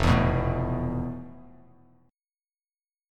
FM#11 chord